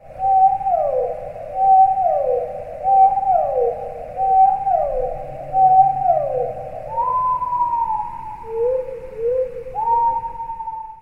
دانلود آهنگ دلفین 12 از افکت صوتی انسان و موجودات زنده
جلوه های صوتی
دانلود صدای دلفین 12 از ساعد نیوز با لینک مستقیم و کیفیت بالا